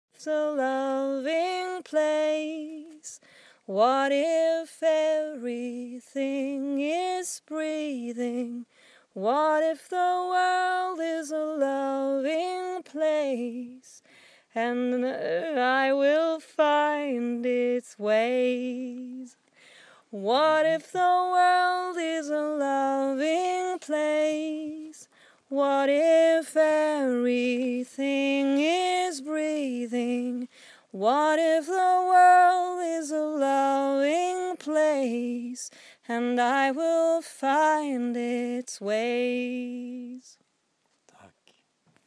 at the end of a summer retreat at Dharmagiri